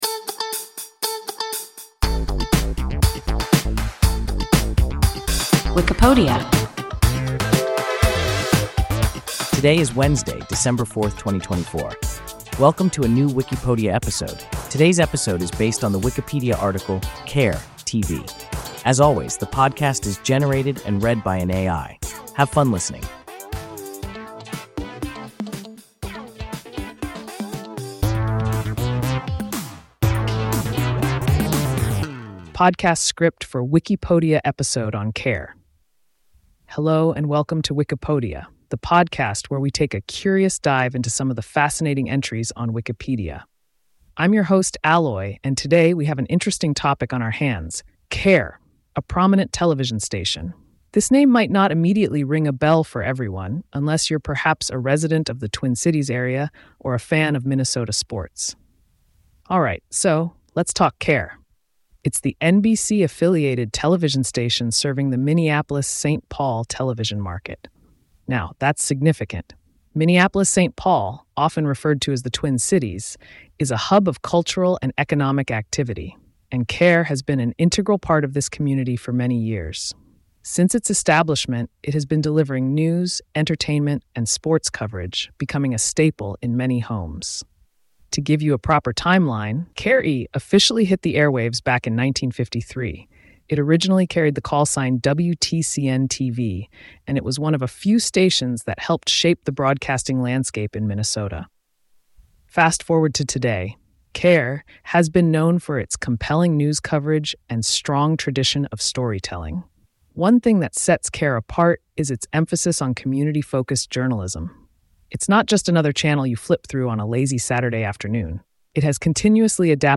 KARE (TV) – WIKIPODIA – ein KI Podcast